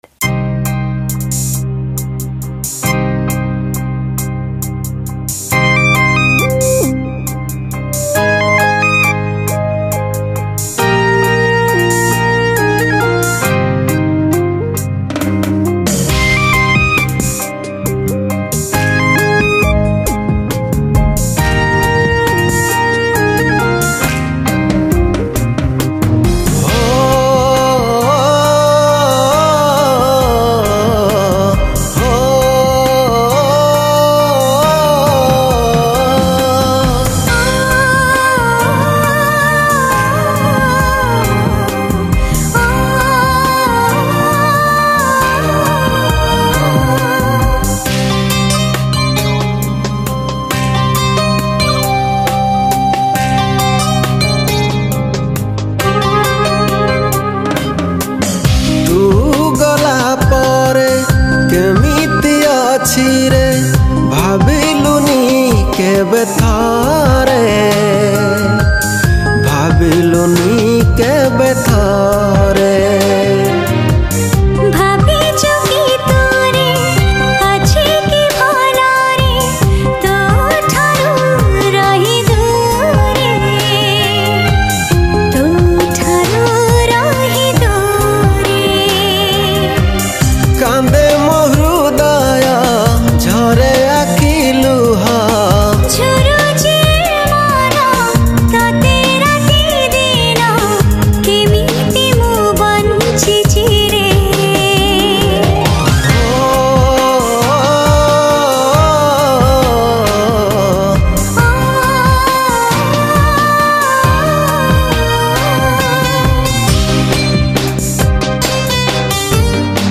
Odia New Sad Song